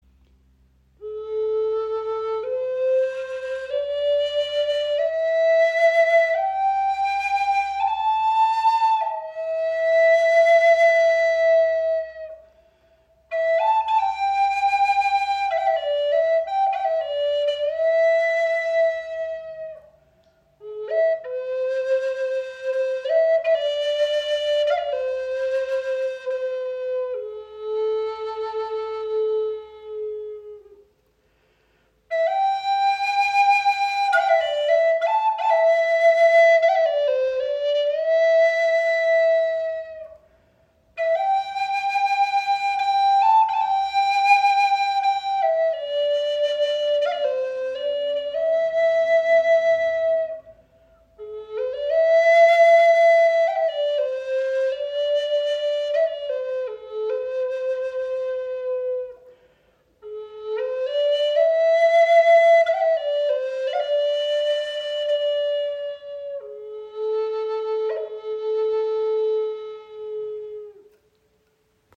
Crow Flöte A-Moll – Ebonisierte Walnuss mit klarem, warmem Klang • Raven Spirit
• Icon Stimmung in A-Moll – auch spielbar in C-Dur
Die Crow Flöte in A-Moll wird aus ebonisierter Walnuss handgefertigt und überzeugt mit ihrem klaren, warmen Klang.
Ihre Stimmung in A-Moll bietet einen warmen, melancholischen Klangcharakter und lässt sich alternativ auch in C-Dur, der parallelen Durtonart, spielen.
High Spirits Flöten sind Native American Style Flutes.